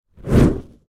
Fire Whoosh Sound Effect Free Download
Fire Whoosh